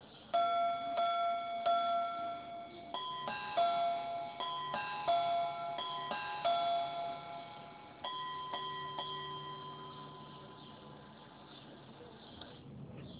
Конечно запись никакая.
На часах 3 часа 48 минут. (3 удара - часы, три строенных удара - четверти, 3 удара - минуты).
Чуть улучшил запись
Мелодичность боя не портит даже качество записи.